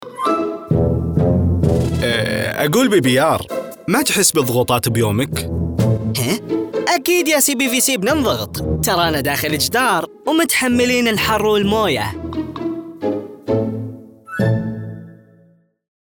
Sprecher
Kommerziell, Tief, Natürlich, Zuverlässig, Corporate
Persönlichkeiten